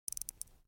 جلوه های صوتی
دانلود صدای ساعت 21 از ساعد نیوز با لینک مستقیم و کیفیت بالا